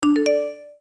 Звуки сообщений iPhone
Звук оповещений iPhone трезвучие